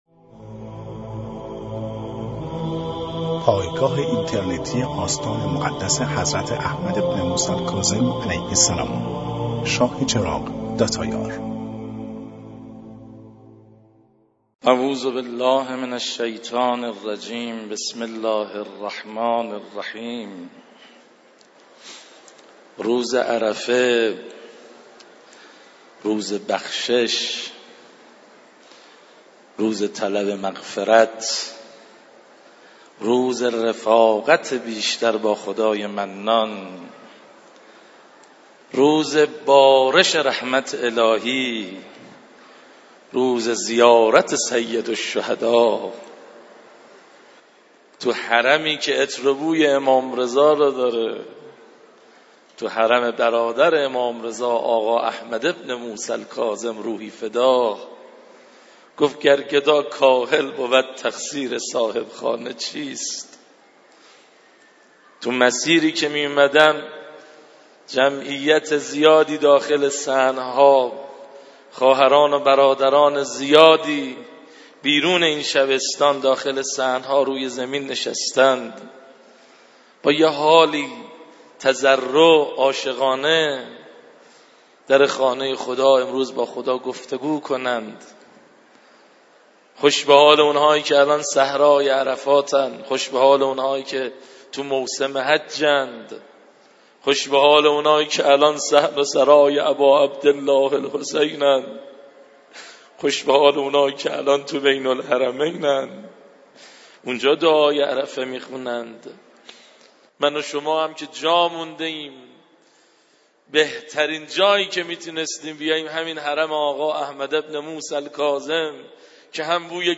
🎙 دانلود فایل صوتی مرثیه‌سرایی و قرائت دعای عرفه